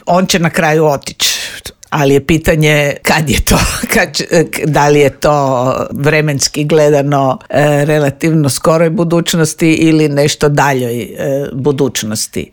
U Intervjuu Media servisa ugostili smo bivšu ministricu vanjskih i europskih poslova Vesnu Pusić koja kaže da je civilno društvo u Srbiji pokazalo da tamo postoji demokratska javnost što se poklopilo sa zamorom materijala vlasti: